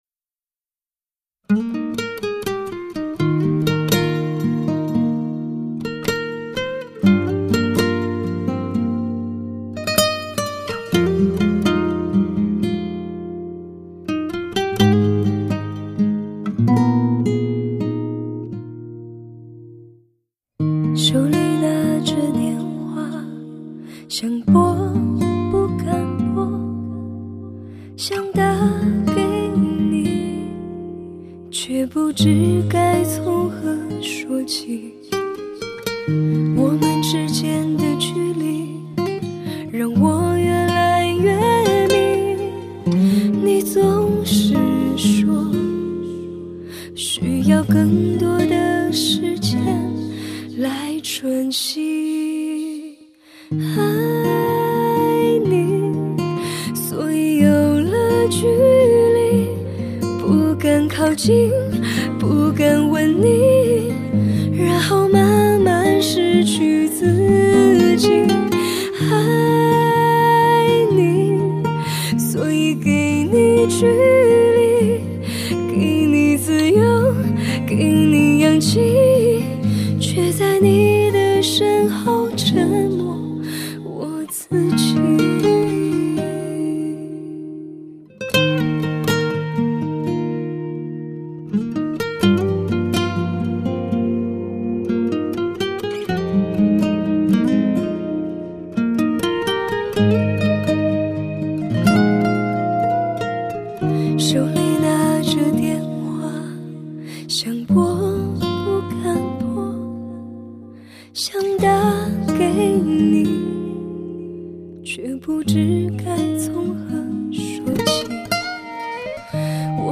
唱功卓越 温暖真挚
情歌、抒情、真诚、入耳、细腻 是这张专辑的风格